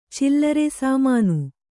♪ cillare sāmānu